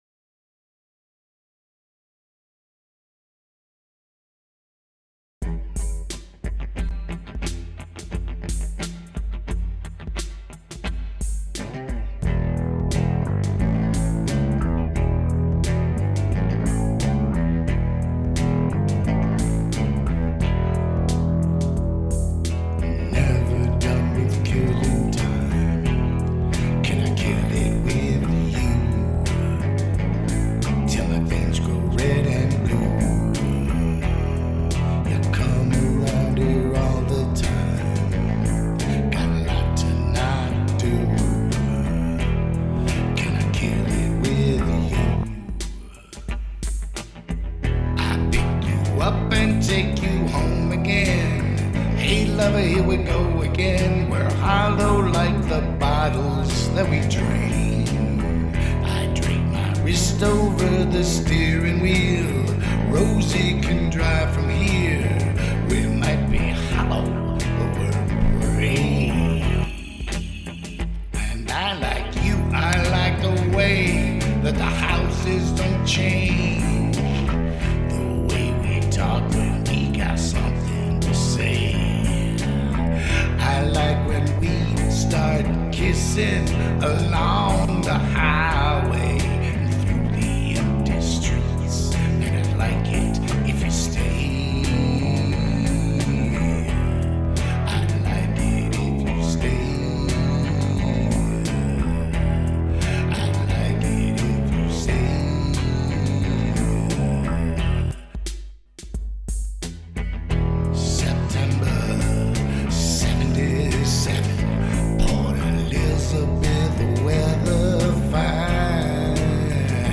(Our first Mashup.